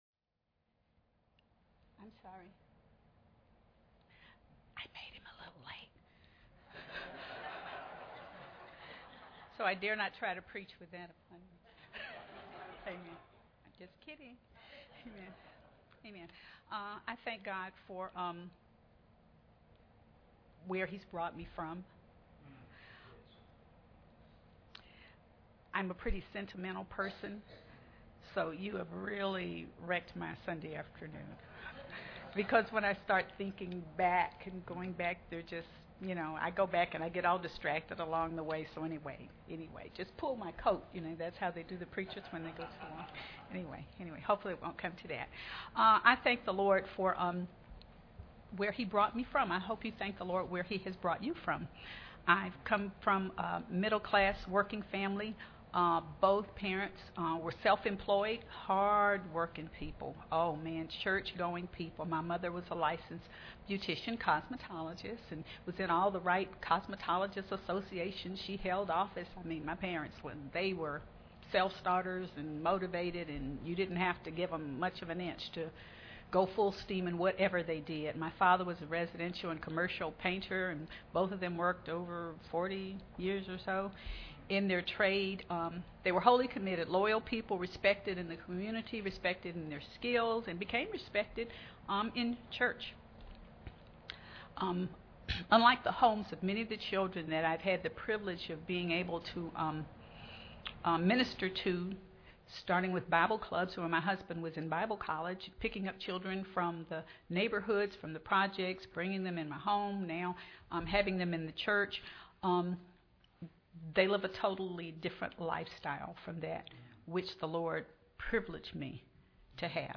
General Service Type: Sunday Evening Preacher